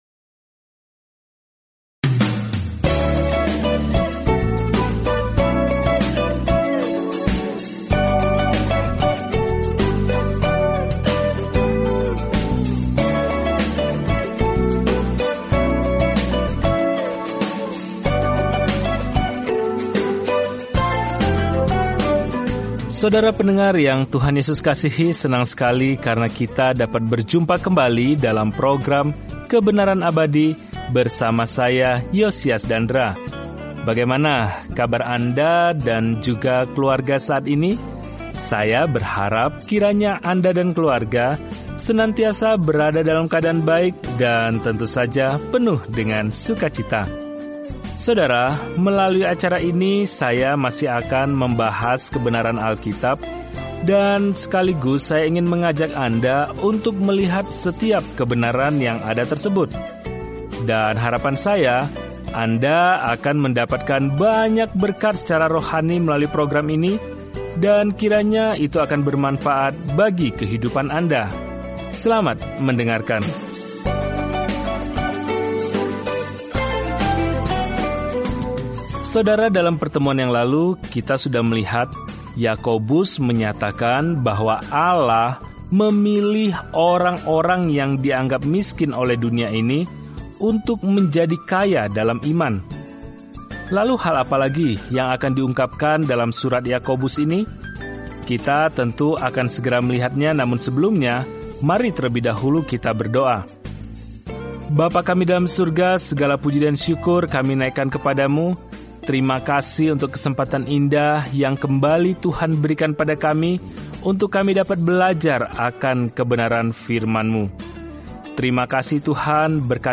Firman Tuhan, Alkitab Yakobus 2:6-14 Hari 8 Mulai Rencana ini Hari 10 Tentang Rencana ini Jika Anda seorang yang percaya kepada Yesus Kristus, maka tindakan Anda harus mencerminkan kehidupan baru Anda; wujudkan iman Anda dalam tindakan. Perjalanan harian melalui Yakobus saat Anda mendengarkan studi audio dan membaca ayat-ayat tertentu dari firman Tuhan.